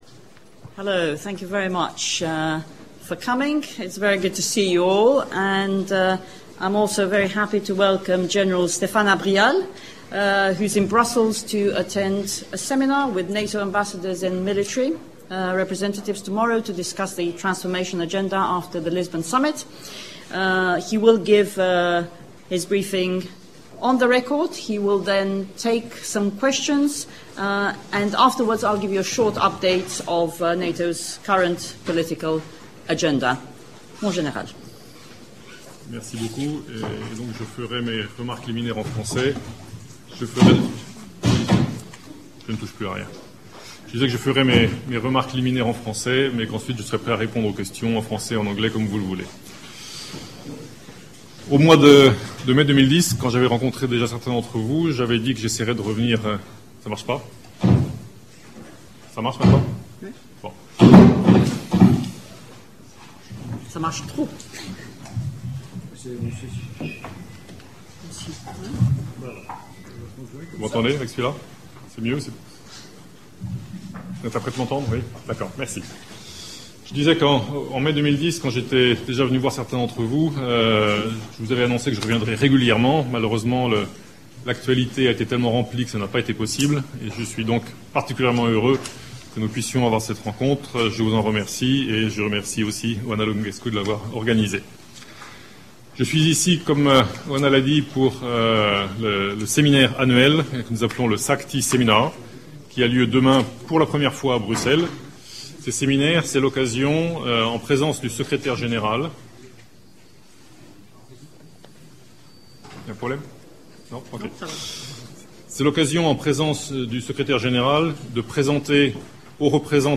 Press briefing by General Stéphane Abrial, Supreme Allied Commander for Transformation (SACT) and the NATO spokesperson Oana Lungescu - 17 February 2011